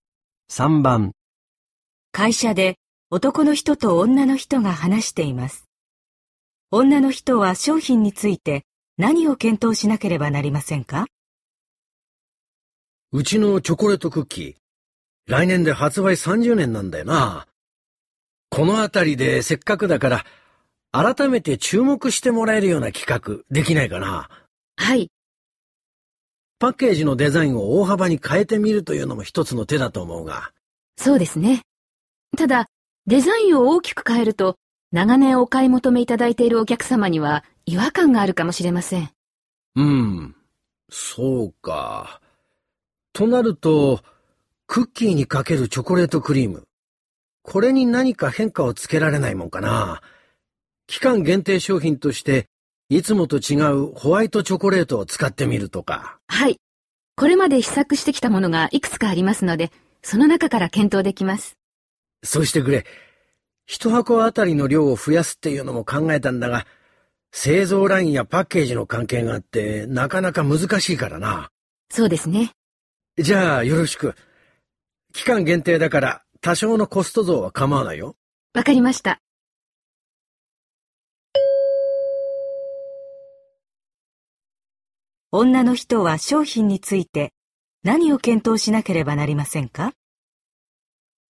类别: N1听力库